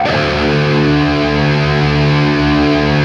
LEAD D 1 LP.wav